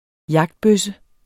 Udtale